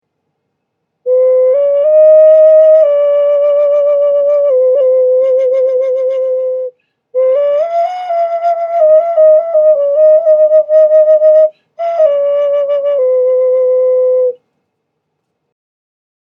Hawk Ocarina Sound Healing Instruments Black Clay Pottery
This Instrument produces a lovely melody.
A recording of the sound of this particular ocarina is in the top description, just click on the play icon to hear the sound.
This musical instrument  is 5 inches by 3 inches across and 2 inches tall.